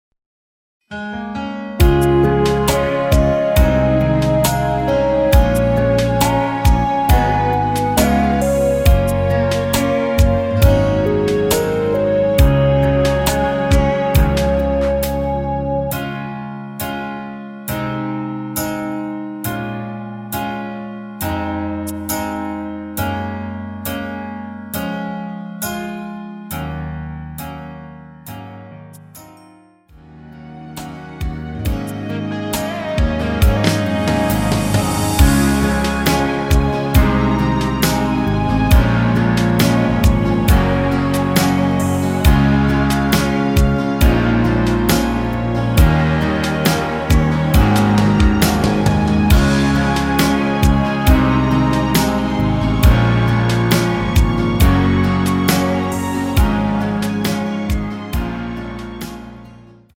원곡의 보컬 목소리를 MR에 약하게 넣어서 제작한 MR이며